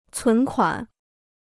存款 (cún kuǎn) Free Chinese Dictionary